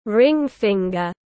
Ring finger /ˈrɪŋ ˌfɪŋ.ɡər/